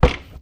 High Quality Footsteps
STEPS Wood, Reverb, Walk 32, Creaky.wav